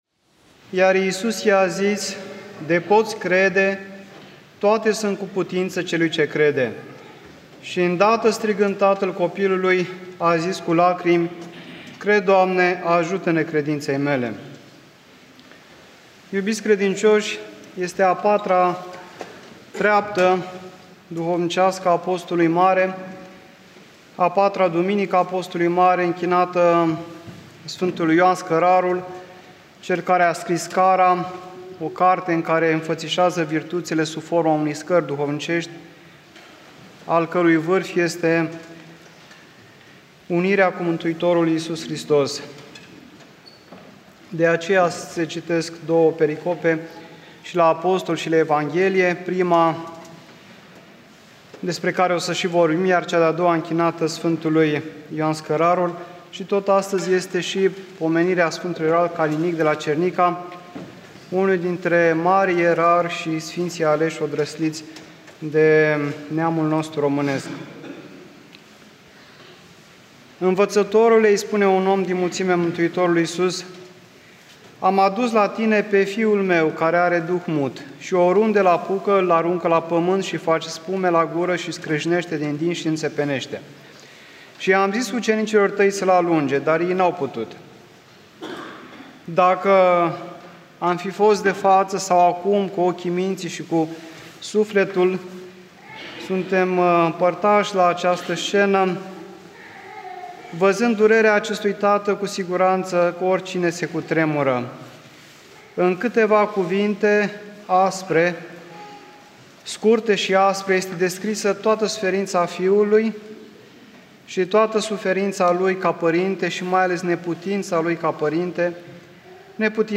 Arhivă de predici la Duminica a IV-a din Post - a Sf. Ioan Scărarul / ortodoxradio